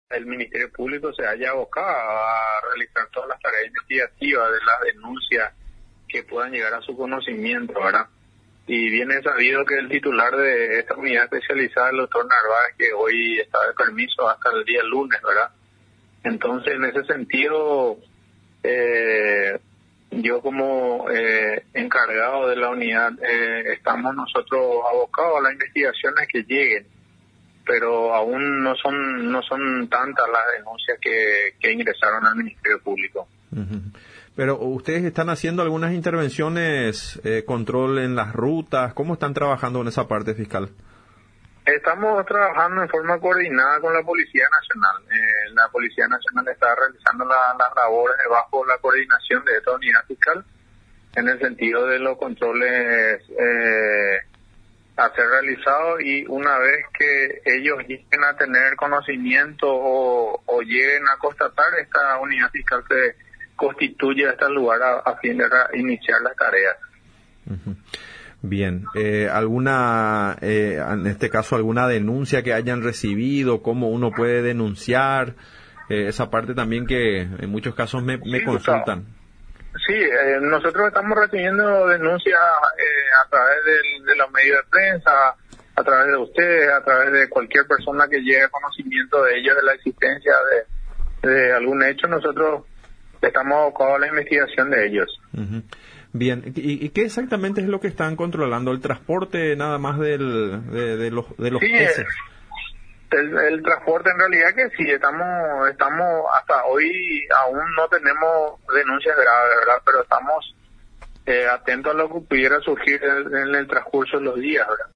El fiscal interino del Medio Ambiente de San Pedro, Carlos Cabrera, en contacto con Radio Nacional San Pedro, informó acerca de las tareas coordinadas que se llevan adelante mediante los estrictos controles de verificaciones y fiscalizaciones en rutas, comercios, transportes y fluviales para que se cumplan efectivamente las normativas ambientales. Las multas por violar el periodo de veda, oscilan entre 3001 y 10000 jornales mínimos.
AGENTE-FISCAL-CARLOS-CABRERA-1.mp3